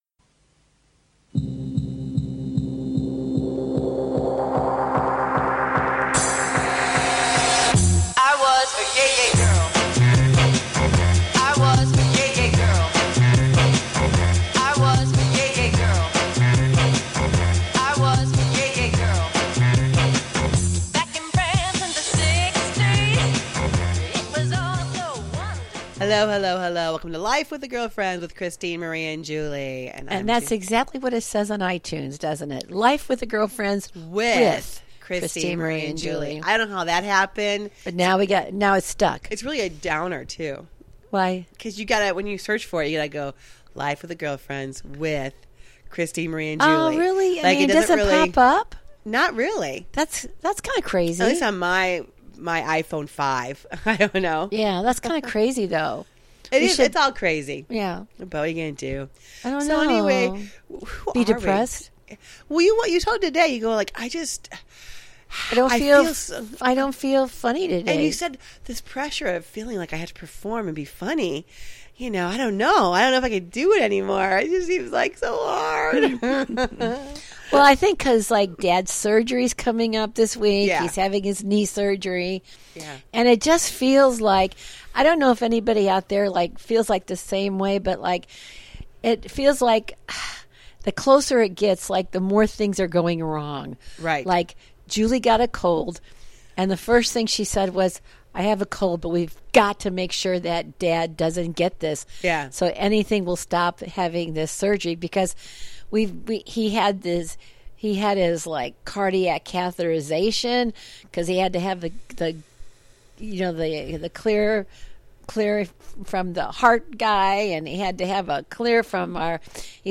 Talk Show Episode, Audio Podcast
And join the girlfriends up close and personal for some daily chat that’s humorous, wholesome, and heartfelt.